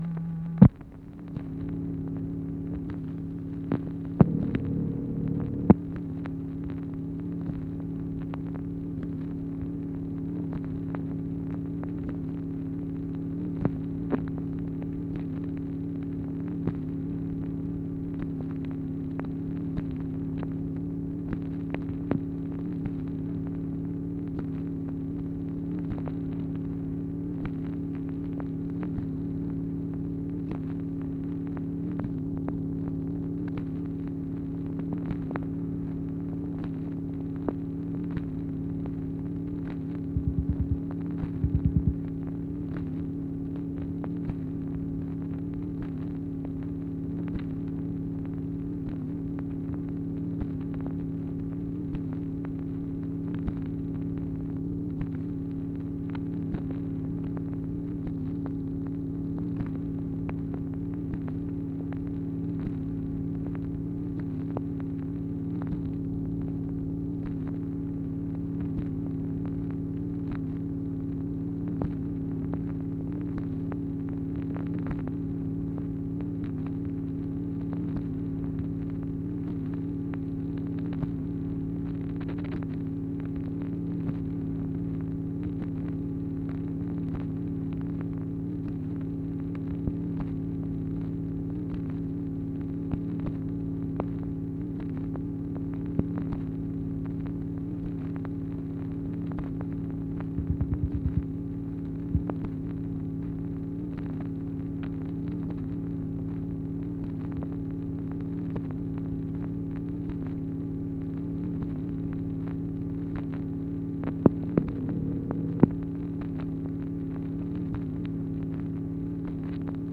MACHINE NOISE, January 8, 1964
Secret White House Tapes | Lyndon B. Johnson Presidency